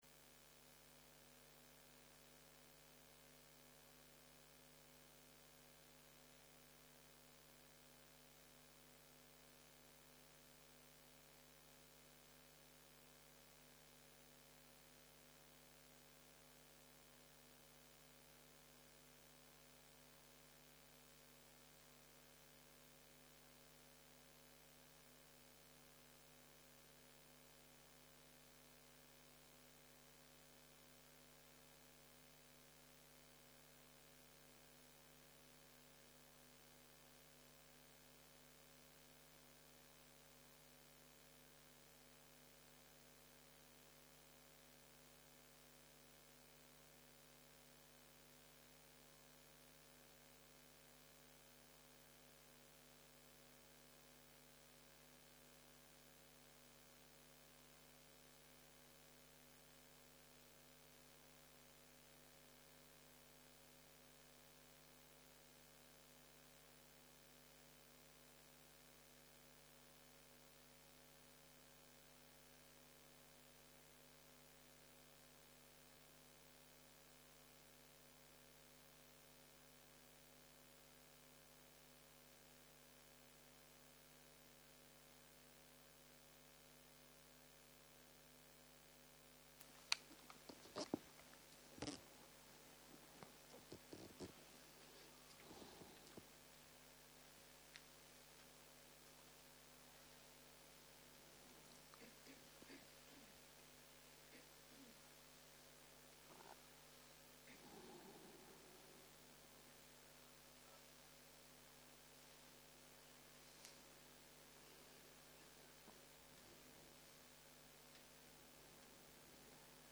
Dharma type: Dharma Talks